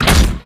Door1.ogg